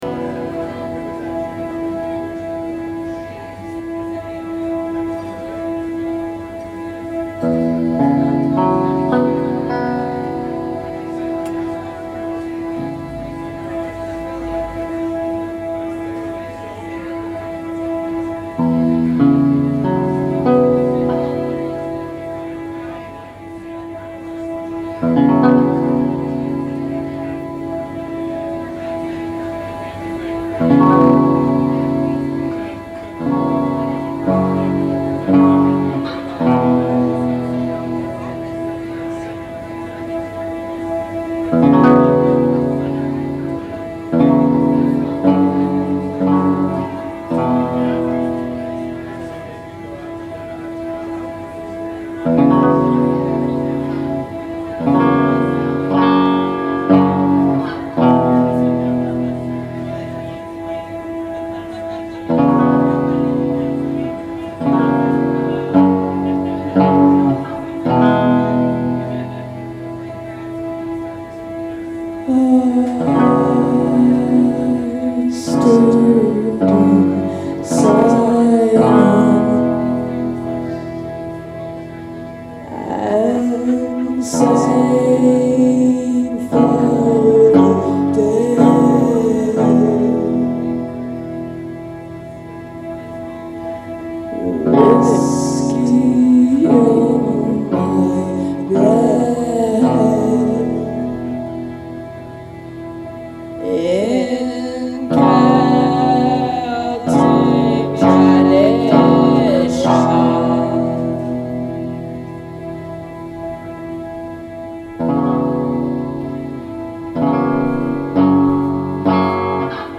Live performances.